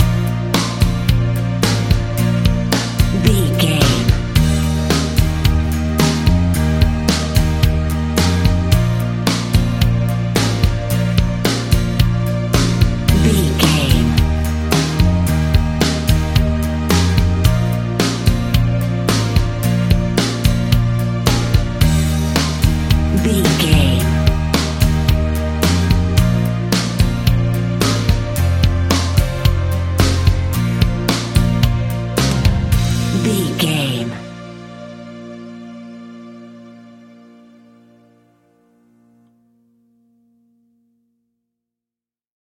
Aeolian/Minor
fun
energetic
uplifting
instrumentals
guitars
bass
drums
organ